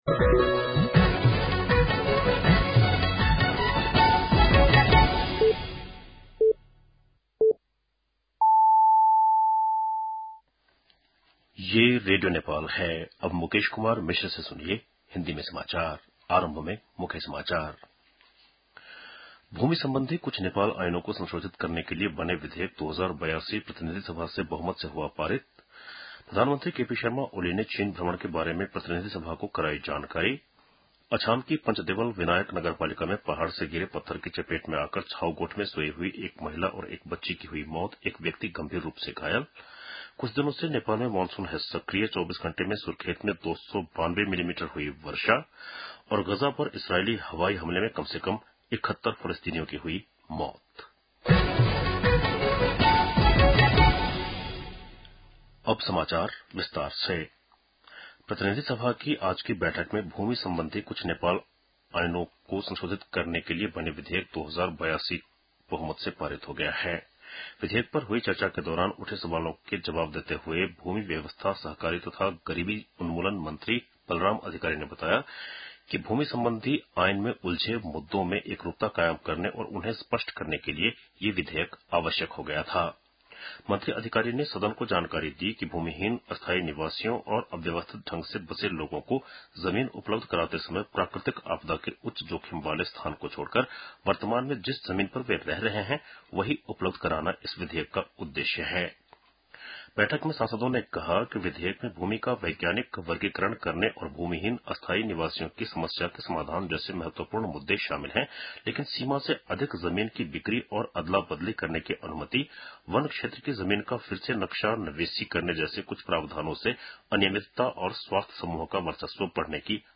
बेलुकी १० बजेको हिन्दी समाचार : १३ भदौ , २०८२